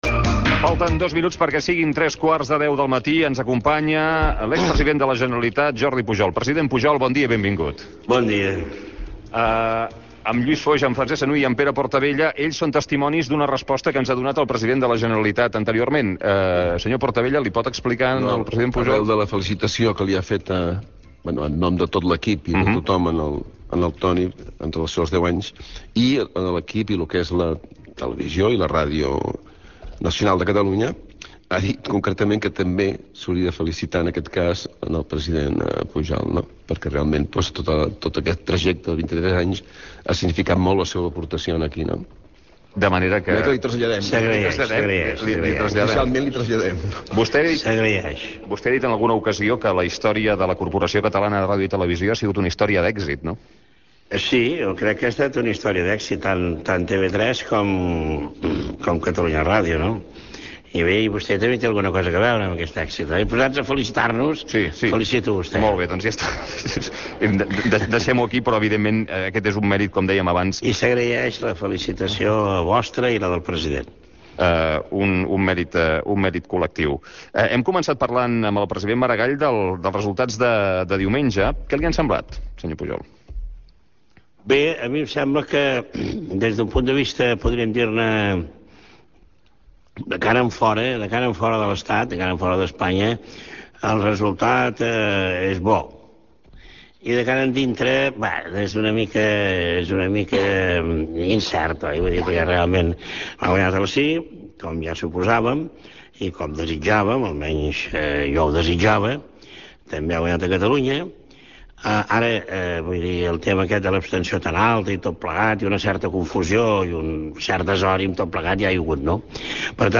Hora, entrevista a l'expresident de la Generalitat de Catalunya Jordi Pujol. Es parla de l'èxit de la Corporació Catalana de Ràdio i Televisió i es fa una valoració del resultat del Referèndum sobre la Constitució Europea a Espanya
Info-entreteniment